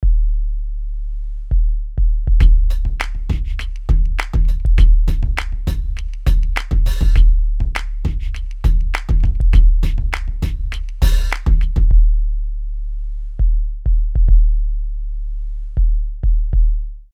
- SubBoomBass RE short demos "HipHop" style -
Pr.105 SubKick (seq)
No additional effects are used and drumloops by BigFish audio.
RP_SBB_105_seq_Subkick_01.mp3